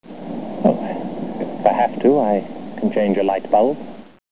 And, of course, an actor who knows how to use his voice as well as Gary Raymond can make even the oddest line sophisticated and memorable.